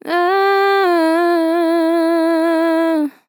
Categories: Vocals Tags: AHHEHEHE, dry, english, female, fill, LOFI VIBES, sample